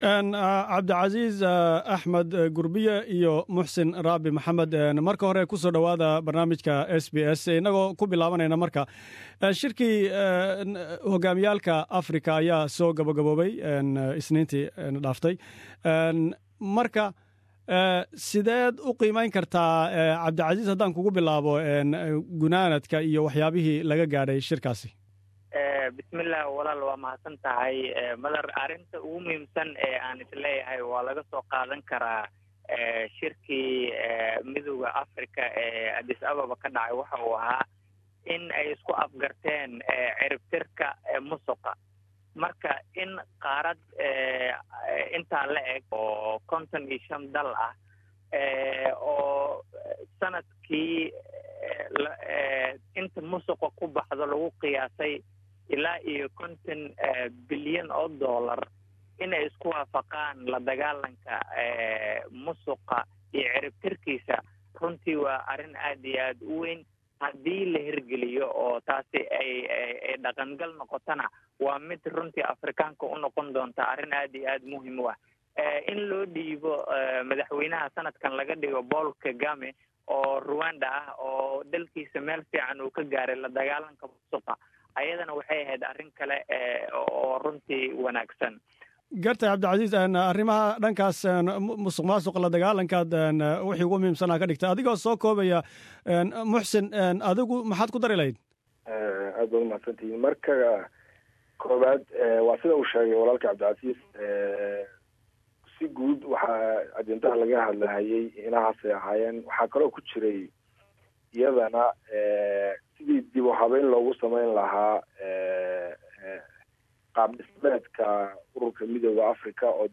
Waraysi diirada saaraya natiijooyinkii Shir-madaxeedkii hogaamiyaalka Midowga Afrika.